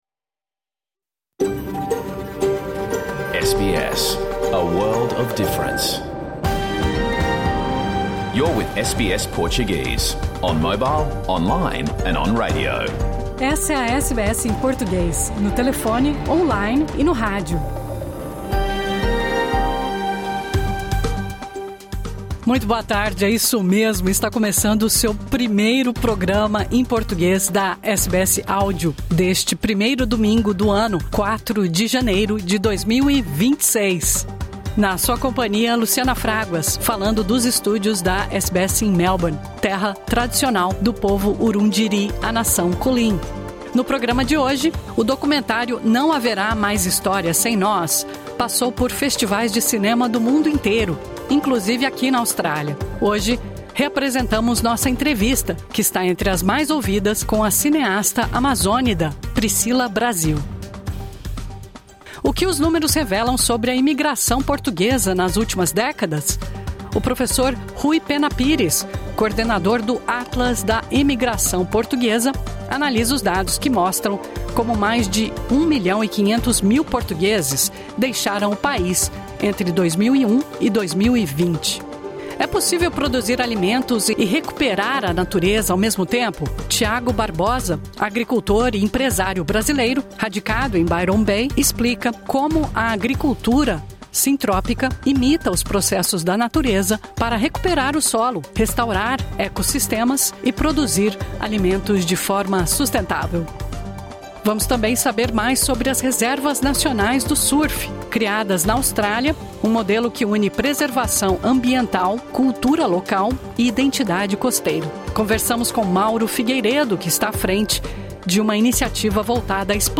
Programa ao vivo | Domingo 4 de janeiro